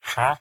Minecraft / mob / villager / haggle2.ogg
haggle2.ogg